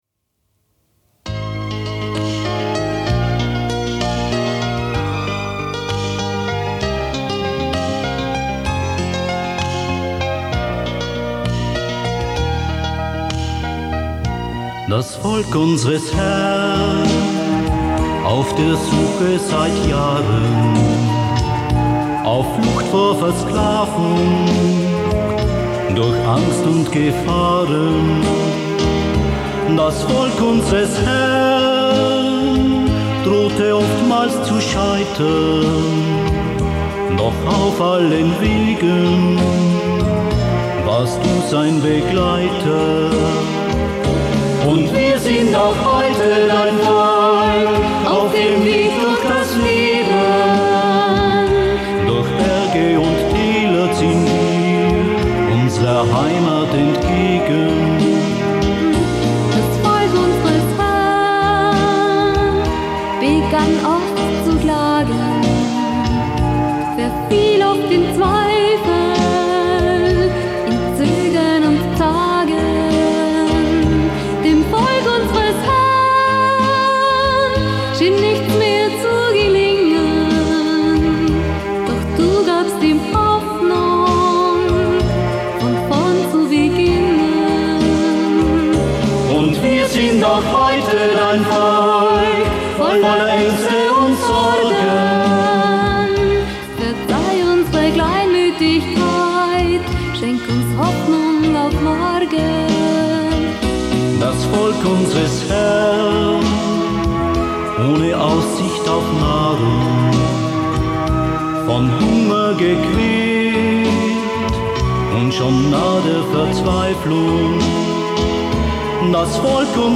Musik: Trad. (Brasilien)